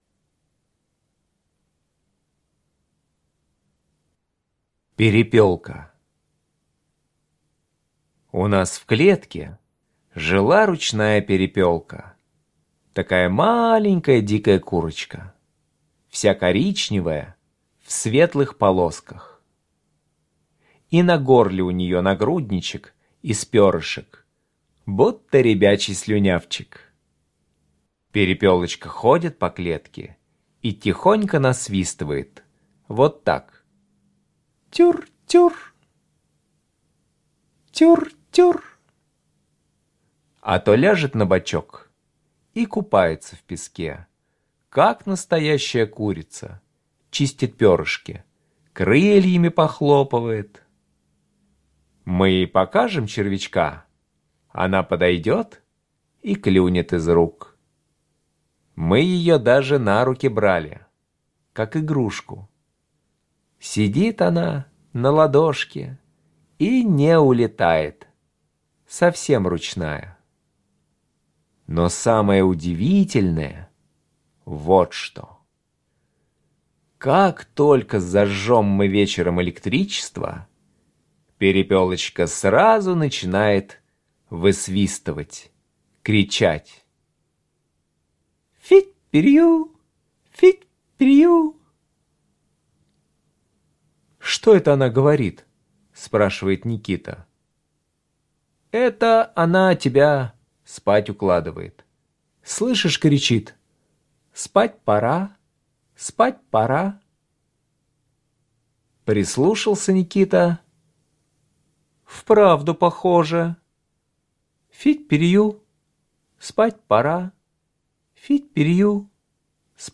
Слушайте Перепелка - аудио рассказ Чарушина Е.И. Перепелочка жила у автор дома и вечером высвистывала: — Фить-пирю!